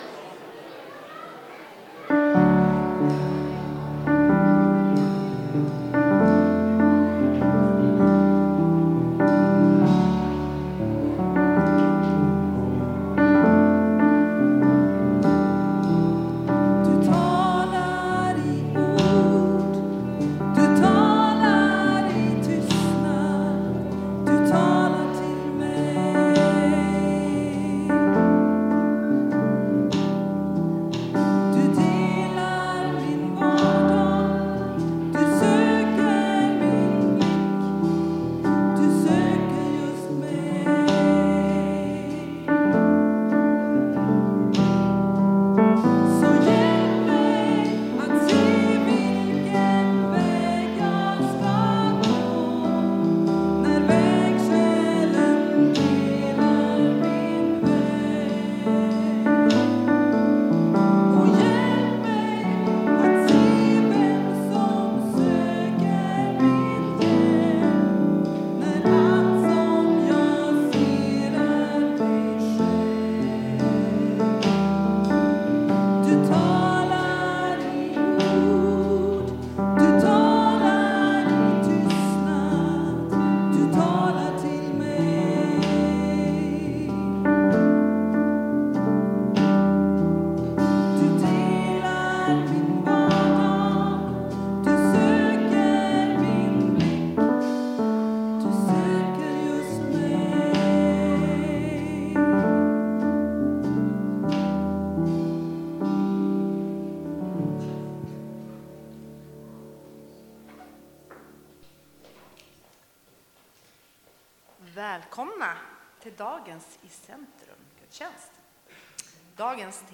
Barnkören Hopp deltog i gudstjänsten. Mästerskapet svarade för musiken.
iCentrum-gudstjänst söndag 18 maj
Många besökare på gudstjänsten i EFS-kyrkan i Skellefteå.